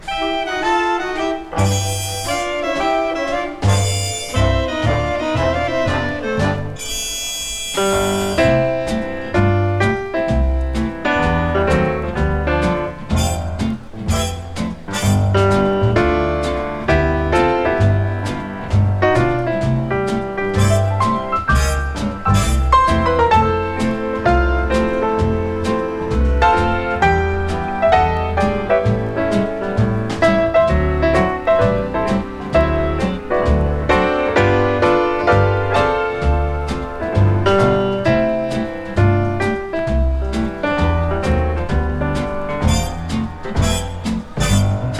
彼らのヒット曲が目白押し、ピアノとスウィートなアンサンブルも聴きどころ多い充実盤。
Jazz, Pop, Easy Listening　USA　12inchレコード　33rpm　Stereo